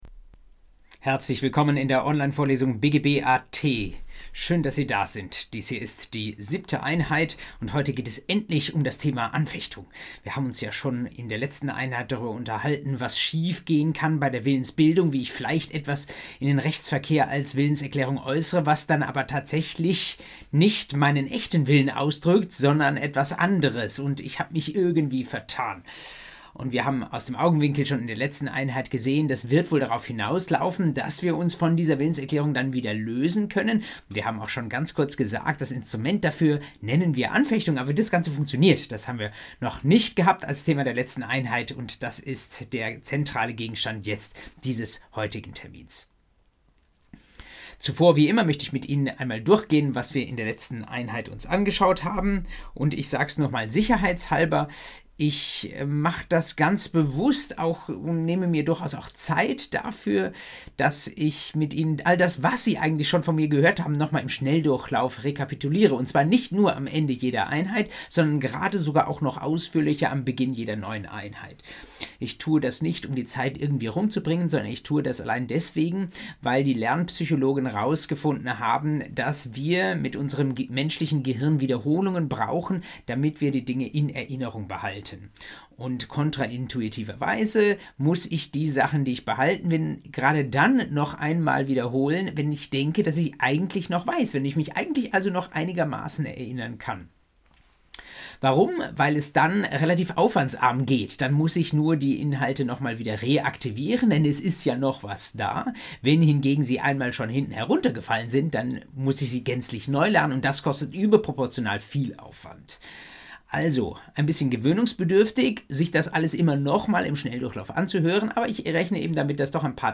BGB AT Folge 7: Anfechtung ~ Vorlesung BGB AT Podcast